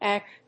イーケー‐ジー